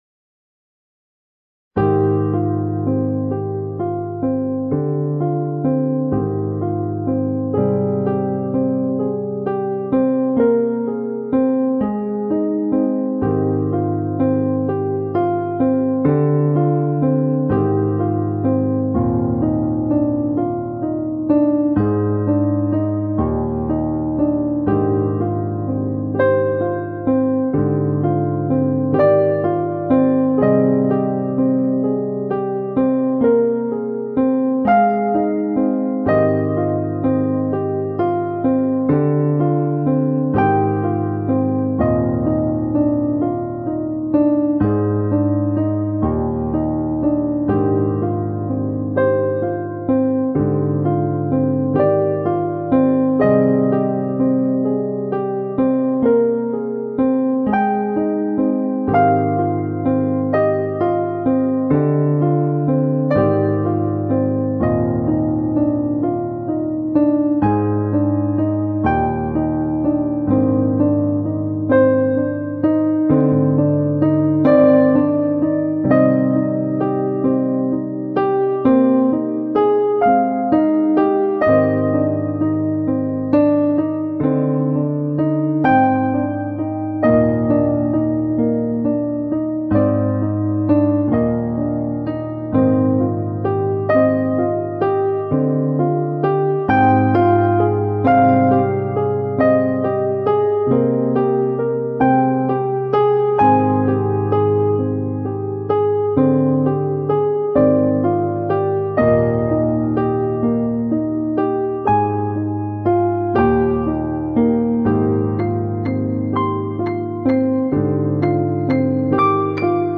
yet another piano song...